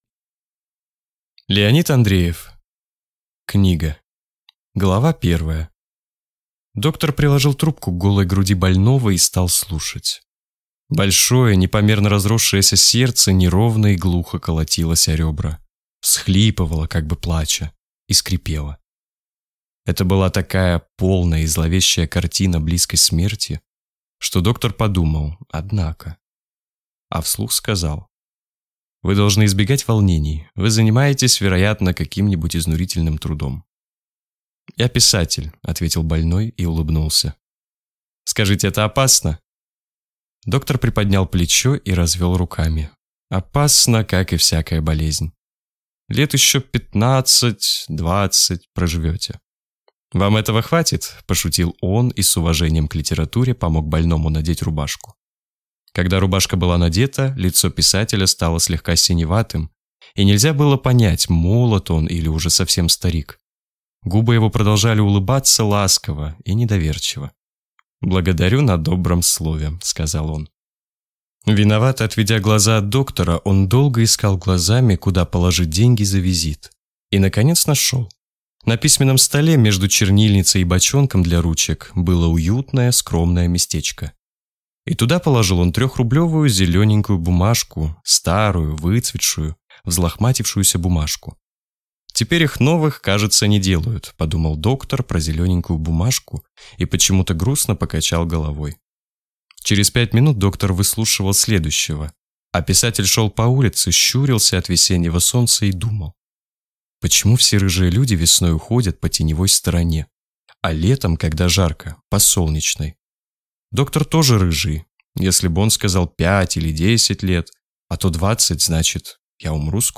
Аудиокнига Книга | Библиотека аудиокниг
Прослушать и бесплатно скачать фрагмент аудиокниги